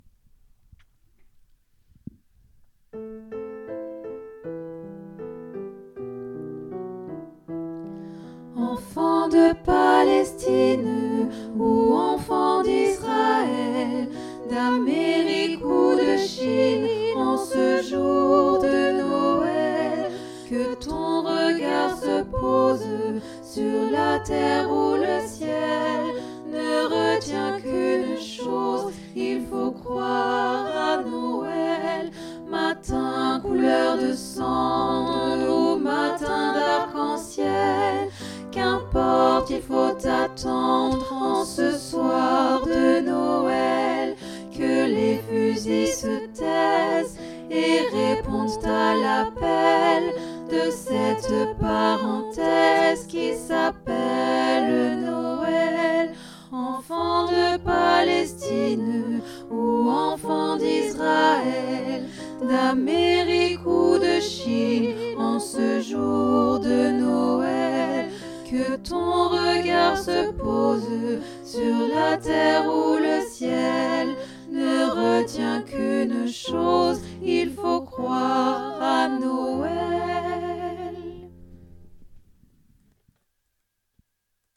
Noël des enfants du monde – J.N Boyer - piano (2022)
ob_5c2b04_noel-des-enfants-tutti.mp3